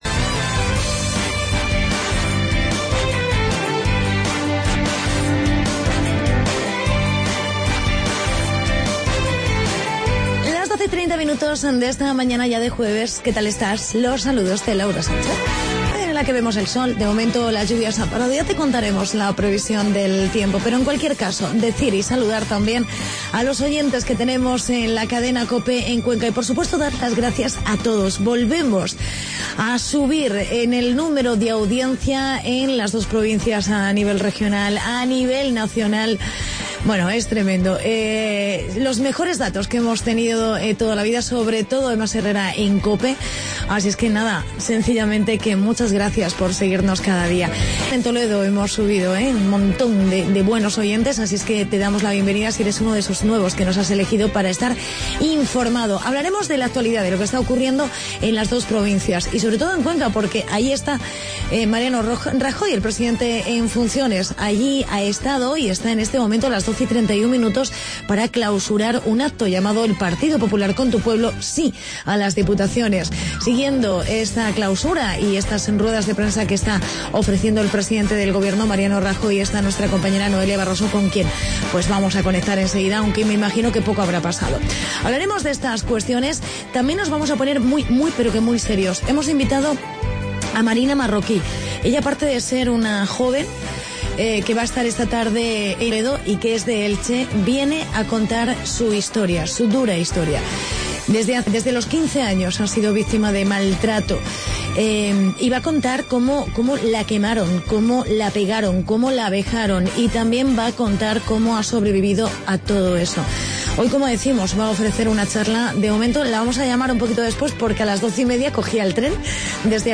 Entrevista
Reportaje